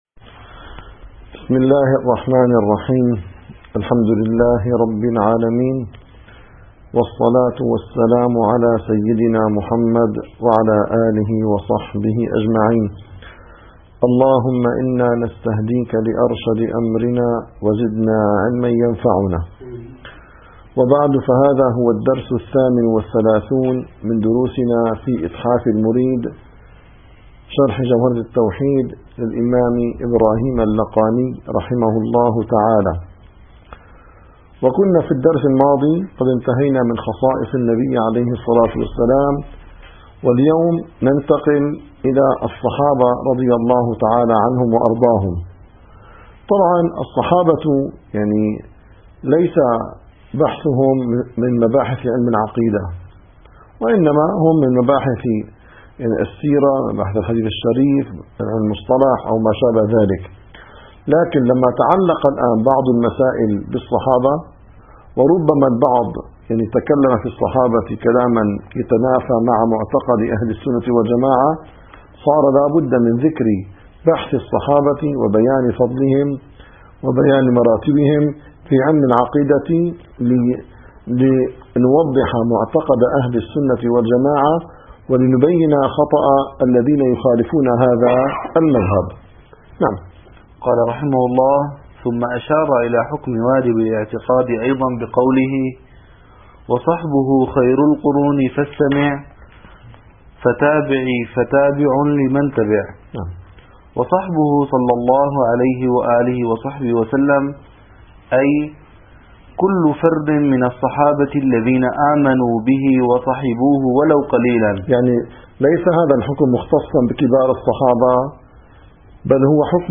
- الدروس العلمية - إتحاف المريد في شرح جوهرة التوحيد - 38- بيان فضيلة أصحابه عليه الصلاة والسلام